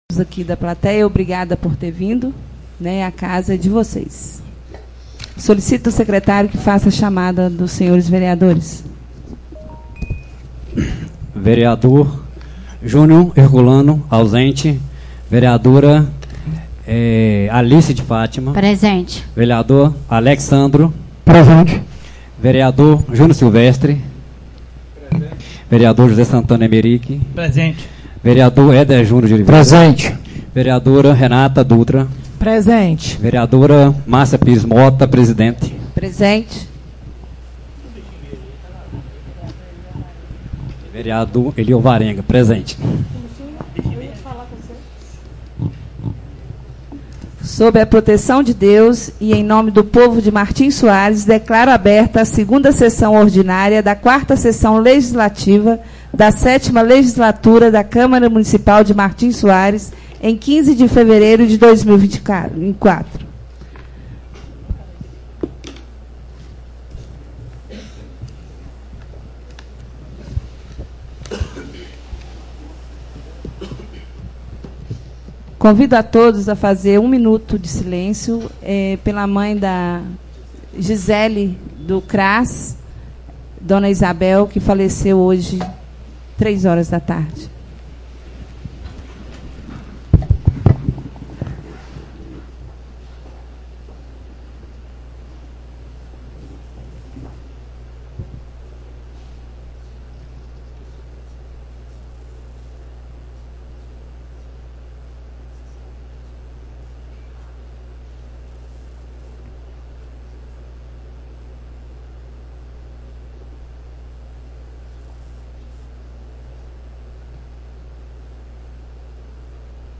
audio 028 - Reunião do dia 05/12/2024